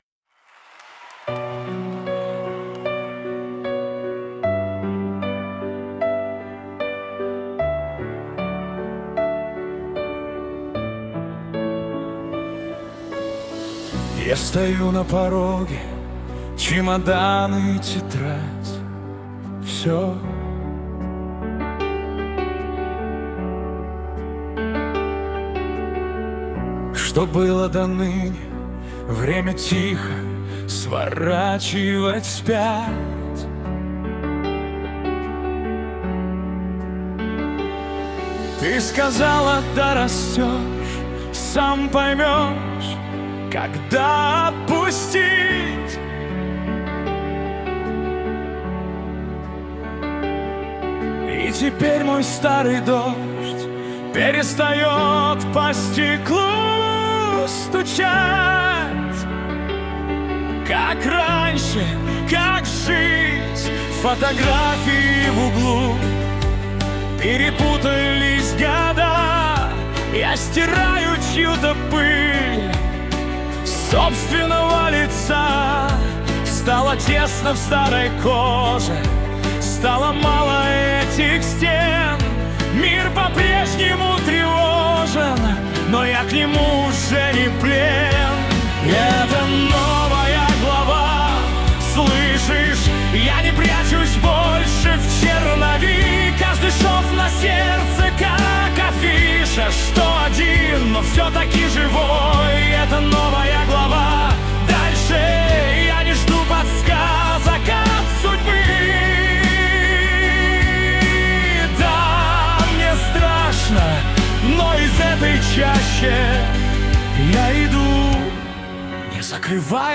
1. Генерация полноценных песен с вокалом и осмысленным текстом